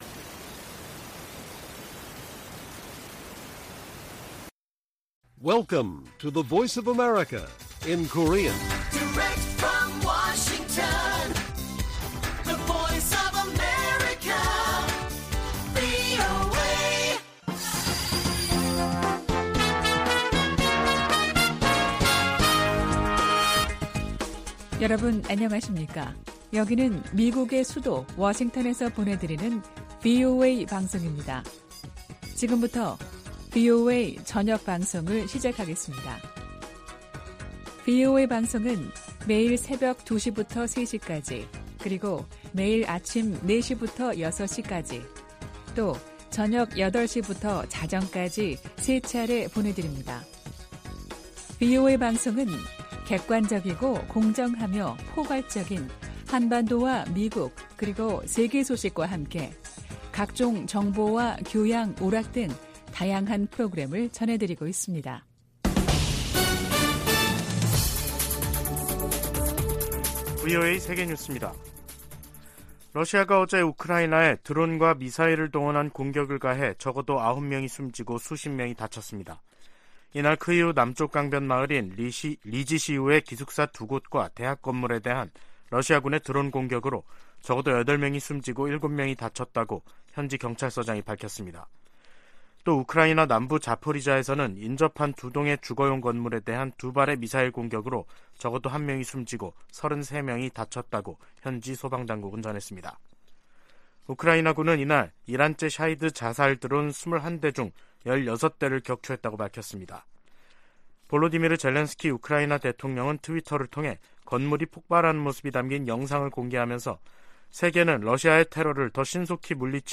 VOA 한국어 간판 뉴스 프로그램 '뉴스 투데이', 2023년 3월 23일 1부 방송입니다. 백악관은 북한의 핵 공격이 임박했다는 징후는 없지만 최대한 면밀히 주시하고 있다고 밝혔습니다. 북한은 국제사회의 비핵화 요구를 핵 포기 강요라며 선전포고로 간주하고 핵으로 맞서겠다고 위협했습니다. 미국 국무부가 한국 정부의 최근 독자 대북제재 조치에 환영의 입장을 밝혔습니다.